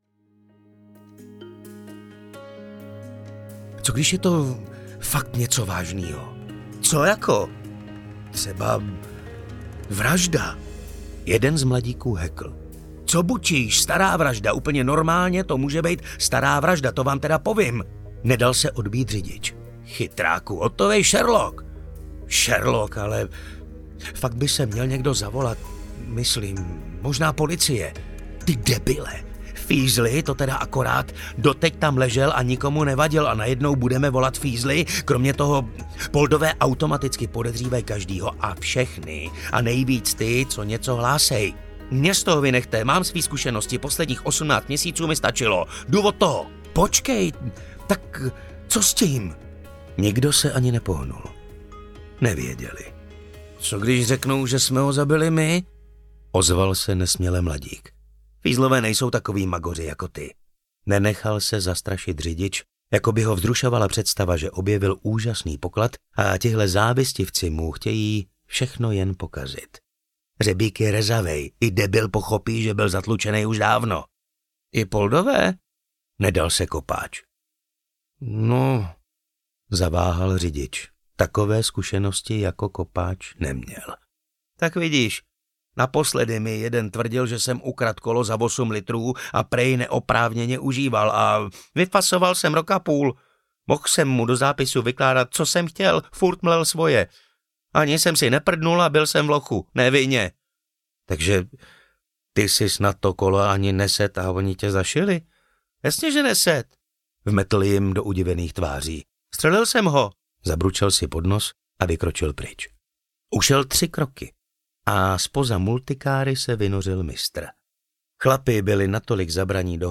Rudý kapitán audiokniha
Ukázka z knihy
• InterpretMartin Stránský